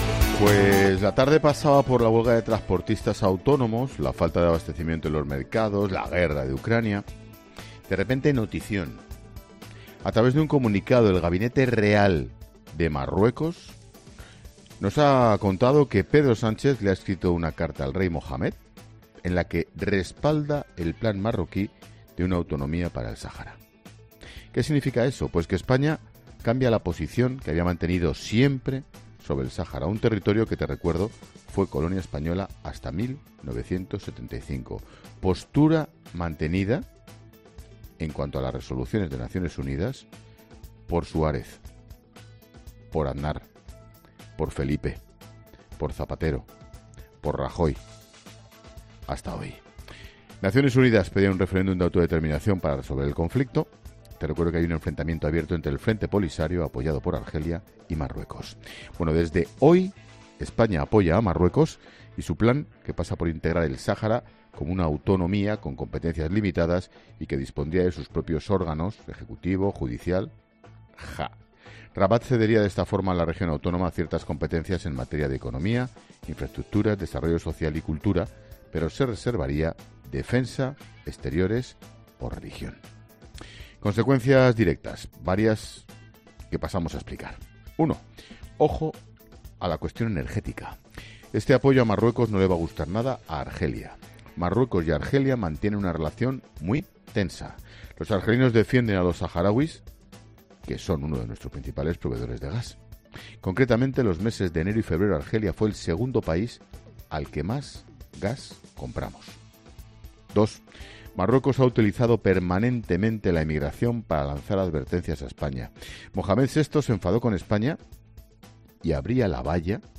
Monólogo de Expósito
El director de 'La Linterna', Ángel Expósito, analiza una de las noticias más destacadas de este viernes